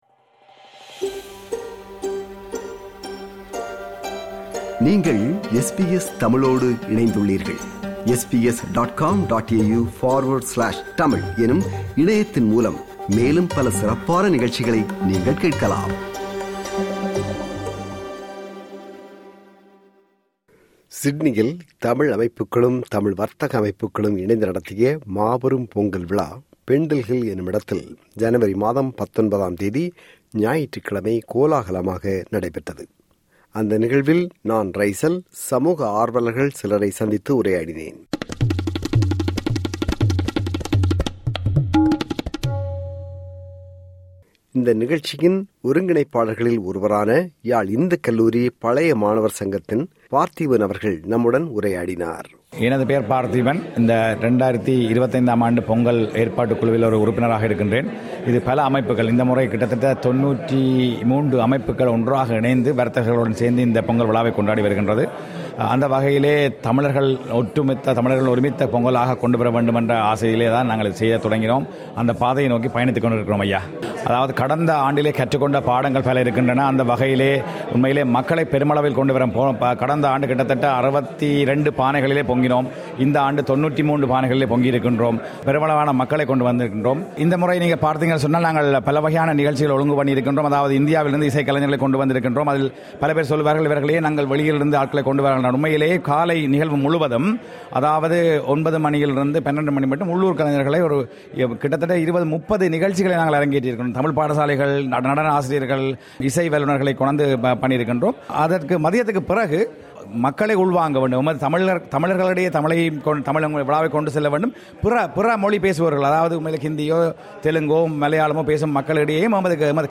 சிட்னியில் தமிழ் அமைப்புகளும், தமிழ் வர்த்தக அமைப்புகளும் இணைந்து நடத்திய மாபெரும் பொங்கல் விழா Pendle Hill எனுமிடத்தில் ஜனவரி மாதம் 19 ஆம் தேதி – ஞாயிற்றுக்கிழமை கோலாகலமாக நடைபெற்றபோது சில சமூகத் தலைவர்கள் மற்றும் சமூக ஆர்வலர்களுடன் உரையாடினோம்.